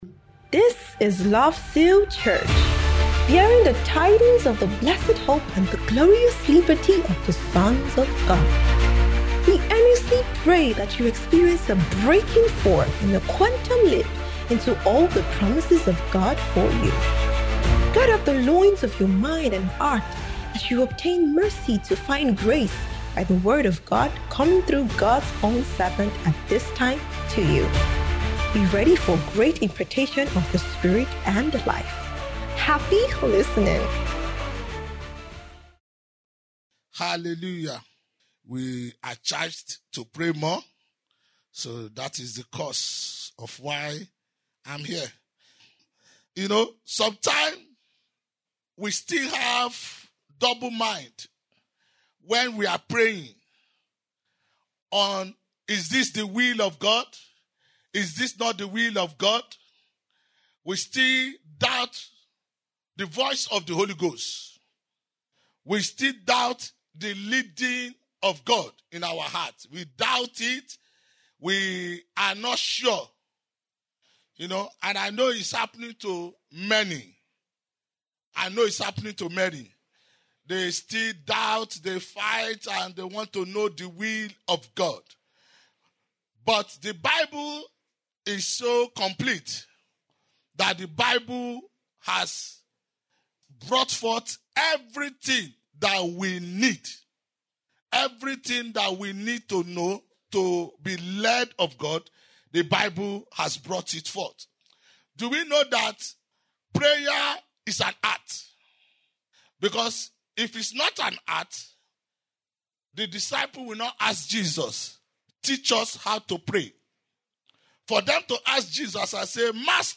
Transfiguration Bootcamp 2025 (D2AM)